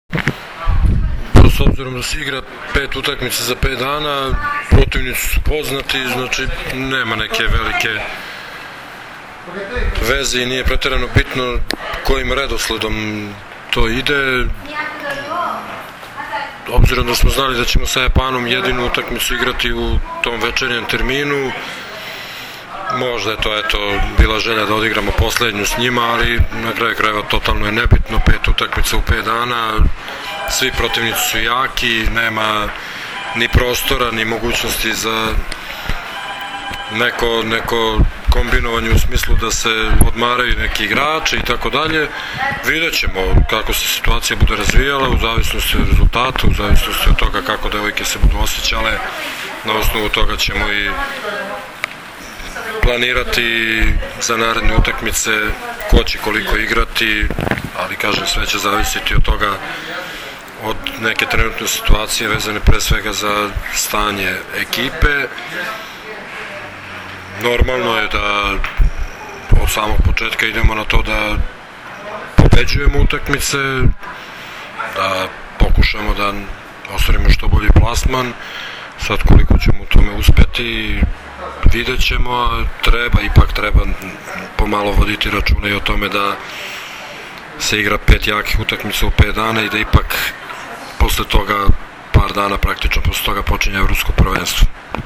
IZJAVA ZORANA TERZIĆA, SELEKTORA SRBIJE